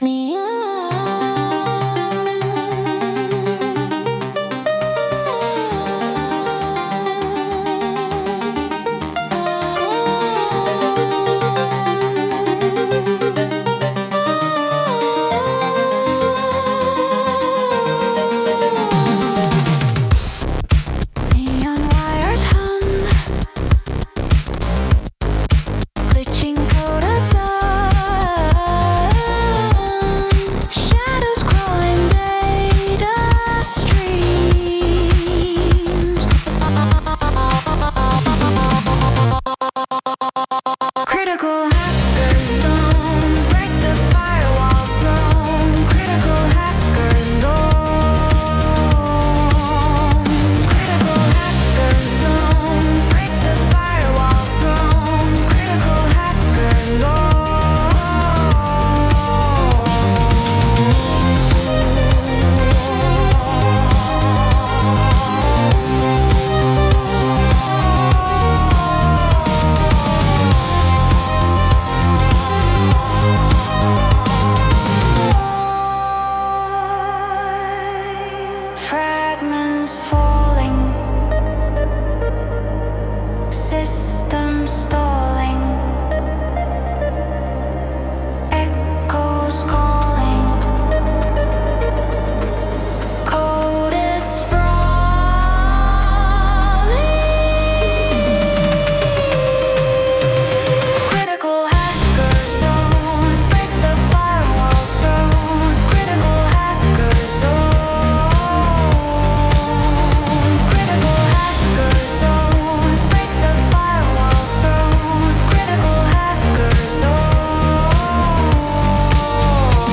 synthwave soundtrack
Genre: Synthwave / Cyberpunk / Retro Electronic